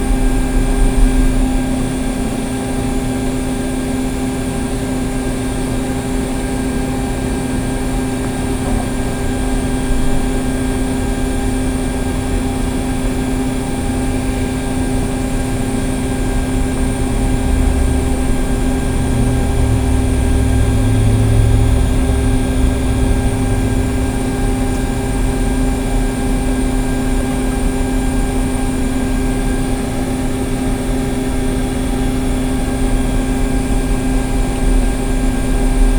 fan_working.wav